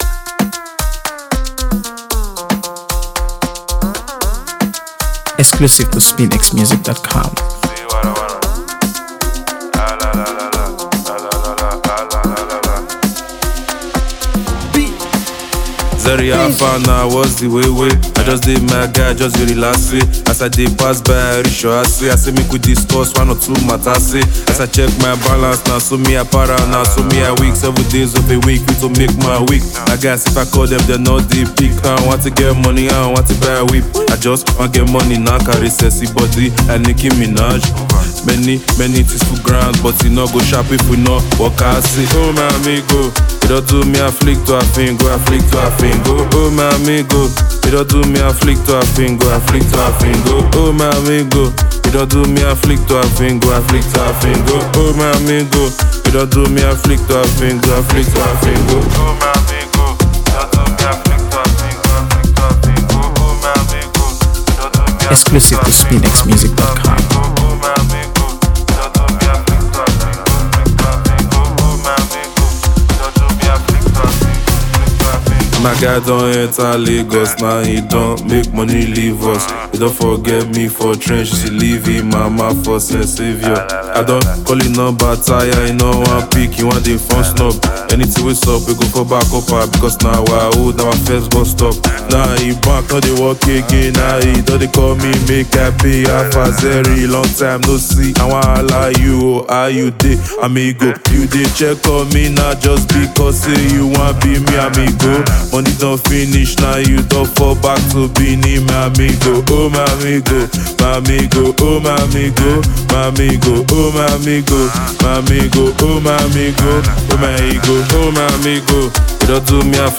AfroBeats | AfroBeats songs
Blending catchy melodies with heartfelt lyrics